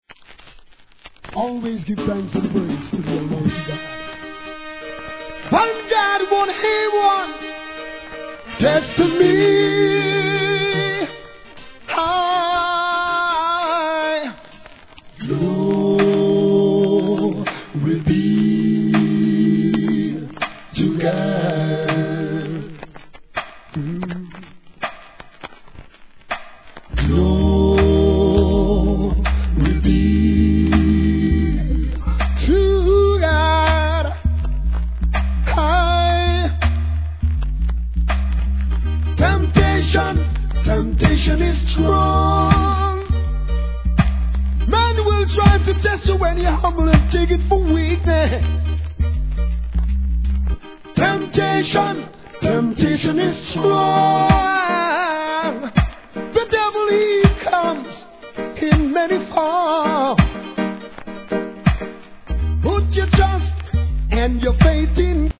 REGGAE
絶妙なコーラスのハーモニー。